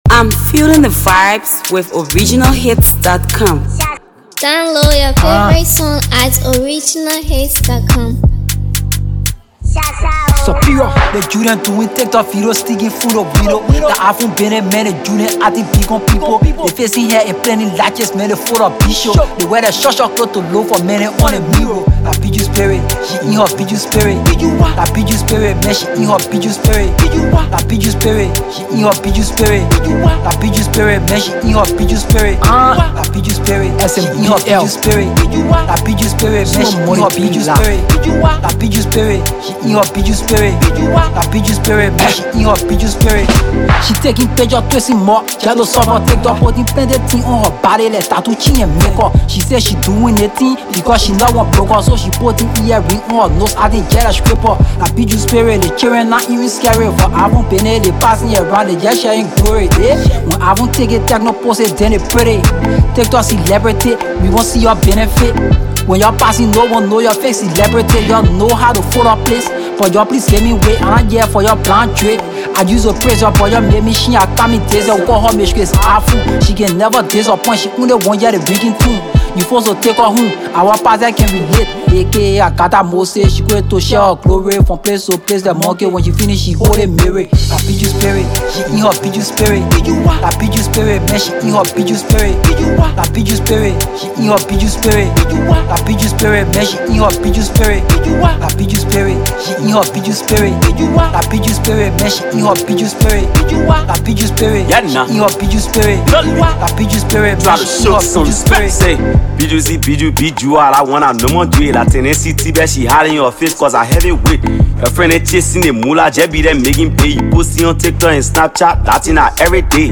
packed with energy and creativity